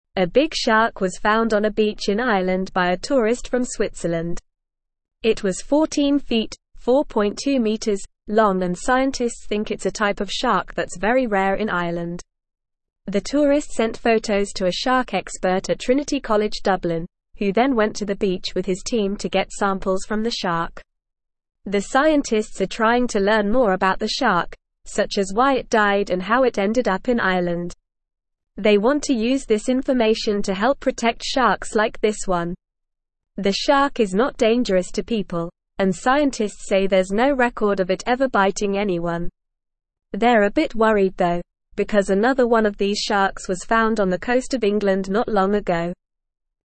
Normal
English-Newsroom-Beginner-NORMAL-Reading-Big-Shark-Found-on-Irish-Beach.mp3